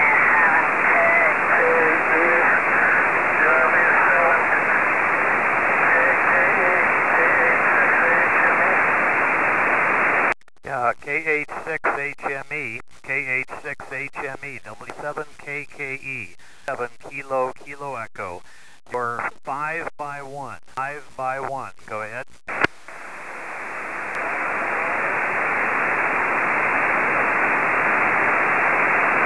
70 cm almost a QSO!
Station consists of: FT-847 with 100 Watt Mirage amps for 2 meters and 70 cm. Antennas are my Oscar array - KLM 2m-14C (7 x 7 crossed Yagi RHCP) and KLM 435-18C (9 x 9 crossed Yagi LHCP) (path did not favor either LH or RH circular polarization.) 70cm SSB mast mounted Preamp.